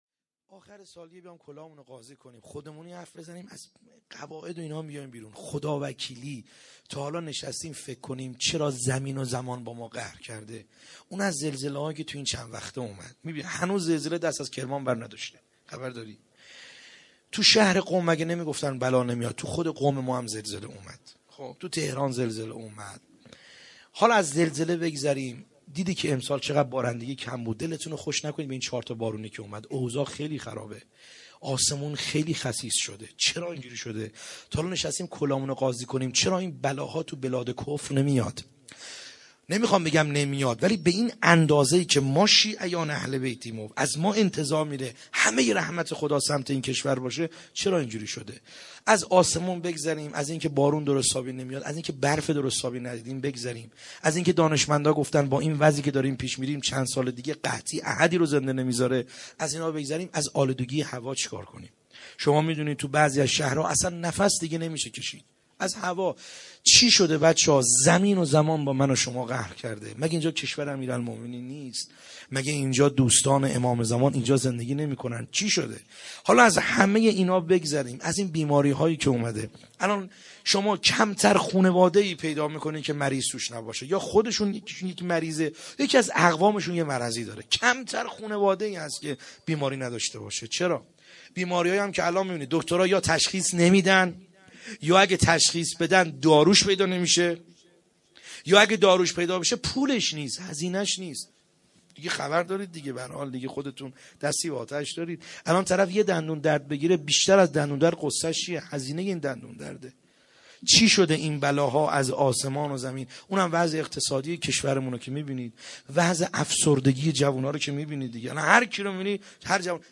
خیمه گاه - بیرق معظم محبین حضرت صاحب الزمان(عج) - مناجات